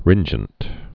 (rĭnjənt)